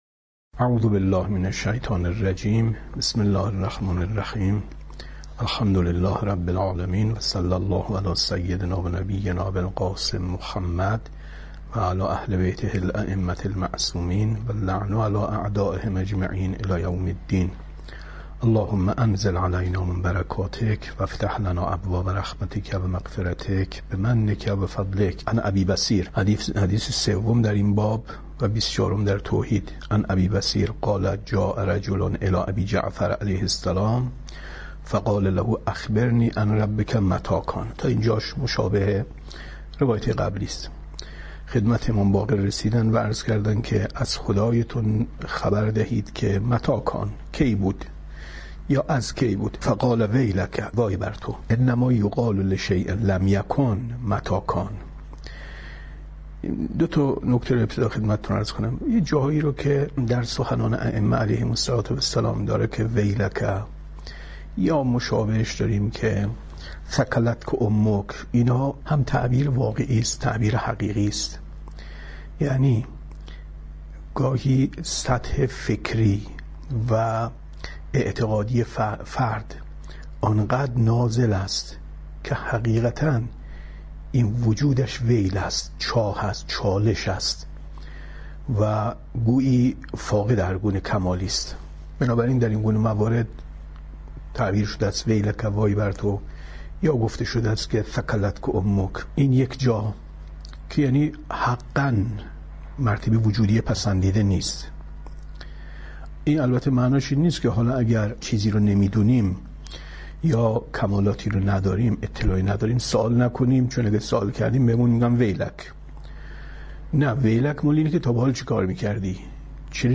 کتاب توحید ـ درس 35 ـ 10/ 10/ 95